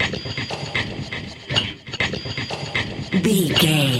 Electronic loops, drums loops, synth loops.,
Epic / Action
Fast paced
Ionian/Major
Fast
aggressive
industrial
groovy
hypnotic
mechanical